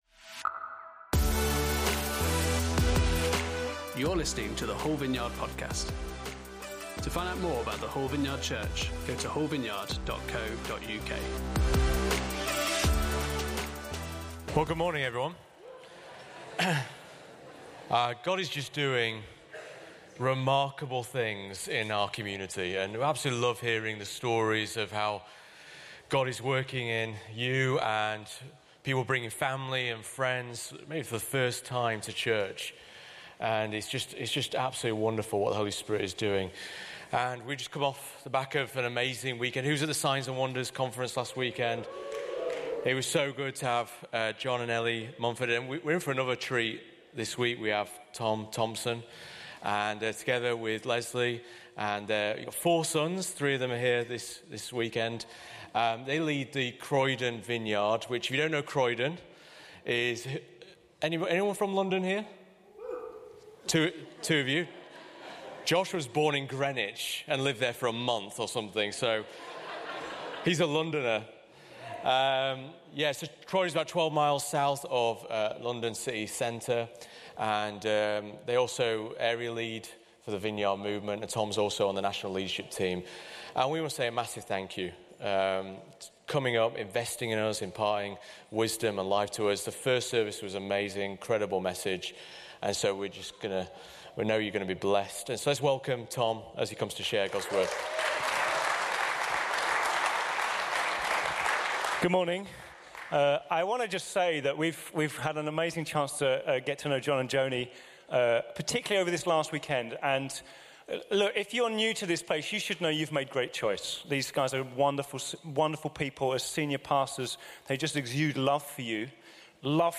19th October 2025 Guest Speaker
Service Type: Sunday Service